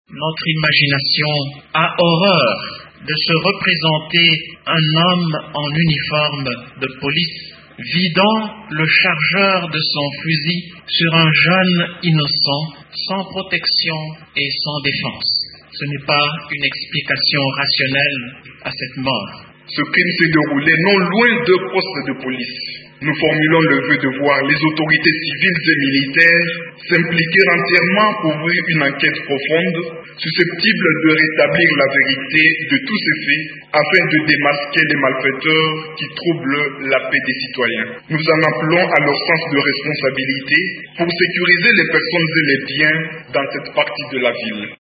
Une messe de requiem a été dite vendredi en mémoire de la victime dans la chapelle du Scolasticat.
Un extrait de l’appel de deux prêtres dans cet élément sonore: